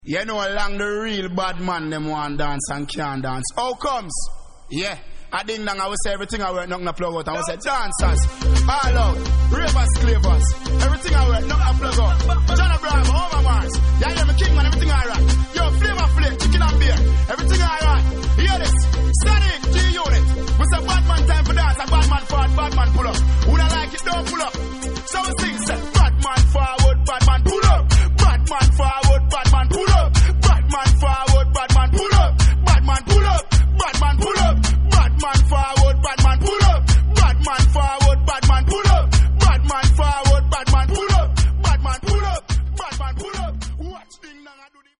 Reggae Ska Dancehall Roots Vinyl Schallplatten Records ...